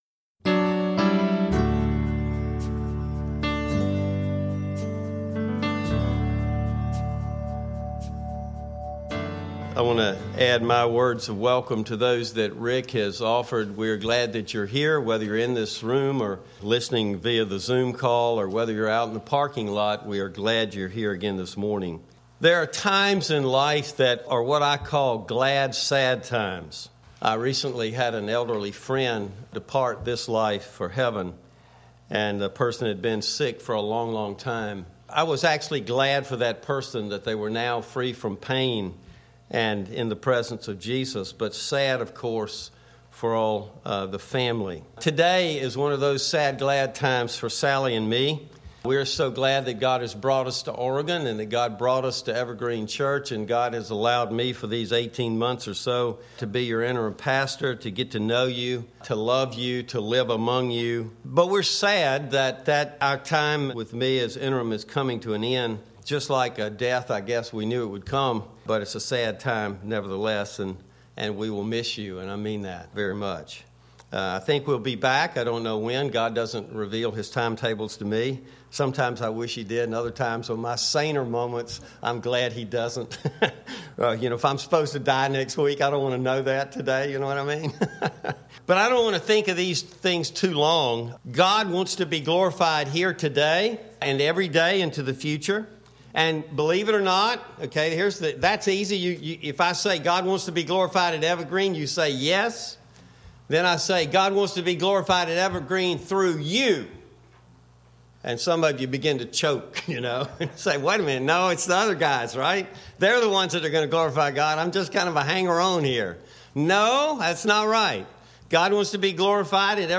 Sermon audio and video are posted.